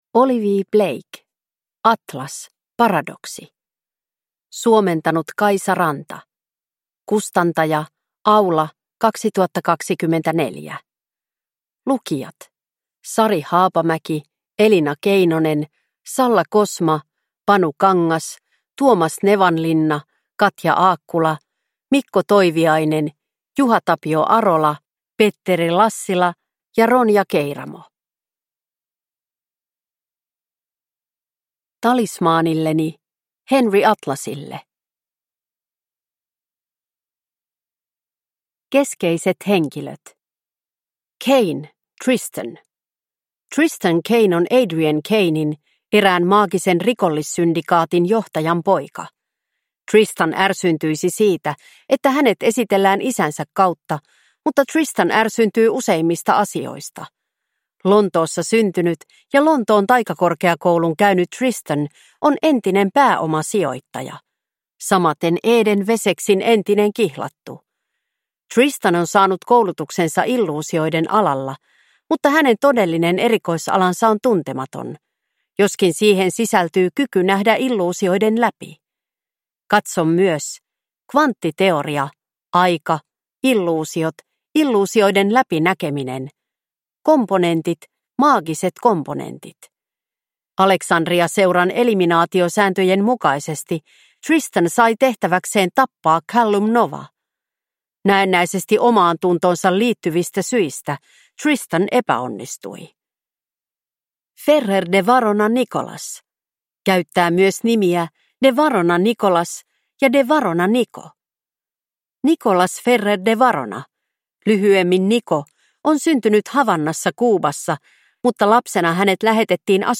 Atlas – Paradoksi – Ljudbok
• Ljudbok